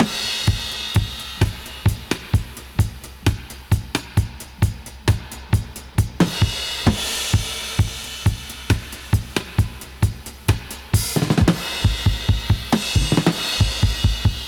131-DUB-01.wav